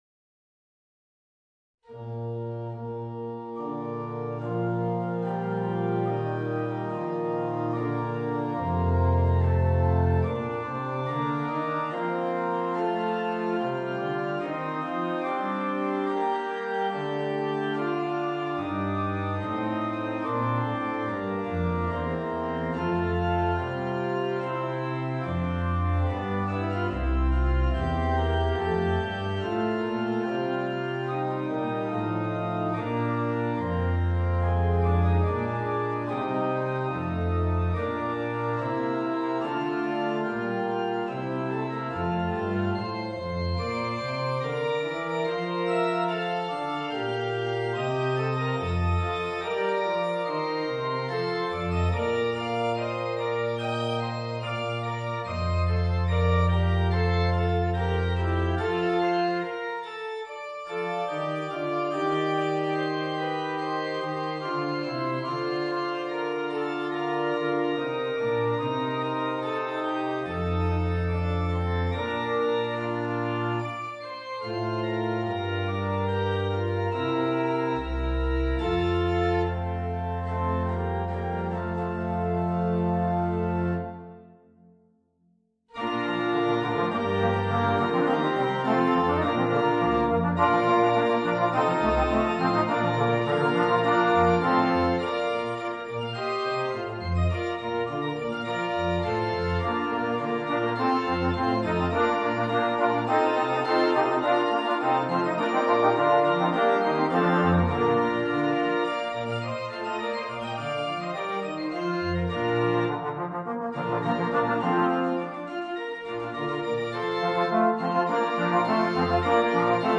Voicing: 2 Trombones and Organ